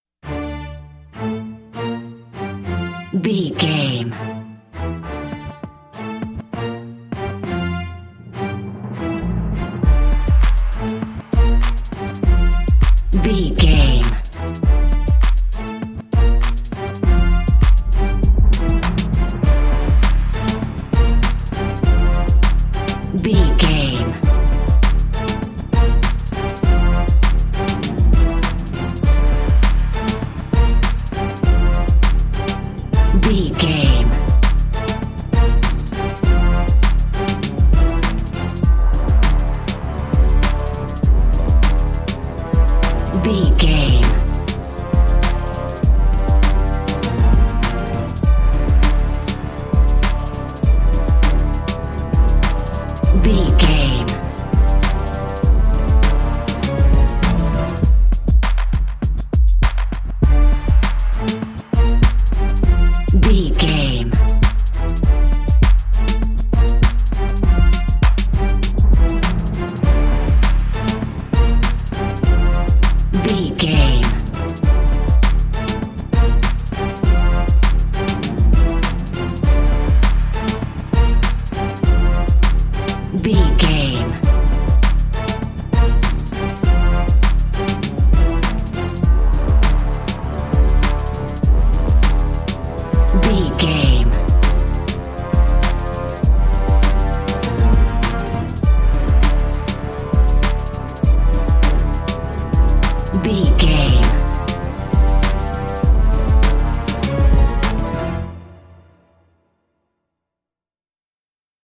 Aeolian/Minor
D
strings
drums
brass
drum machine
orchestra
funky